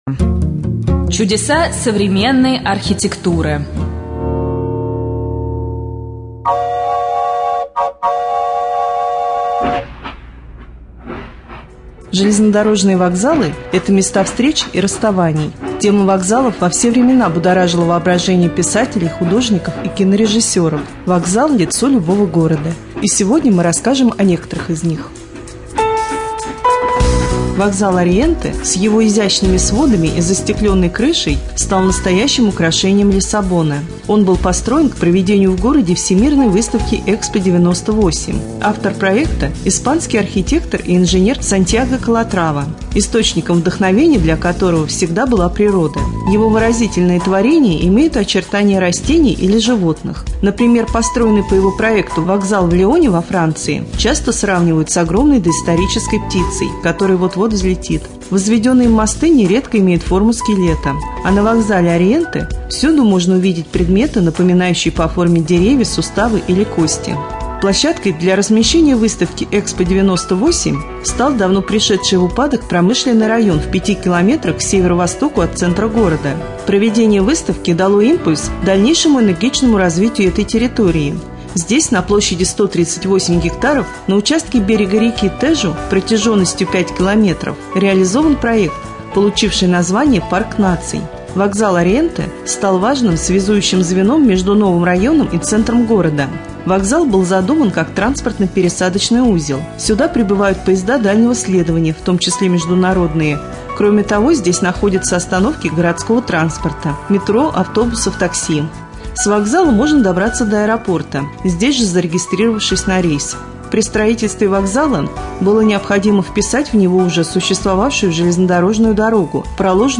24.10.2013г. в эфире раменского радио - РамМедиа - Раменский муниципальный округ - Раменское